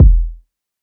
Lust Kick.wav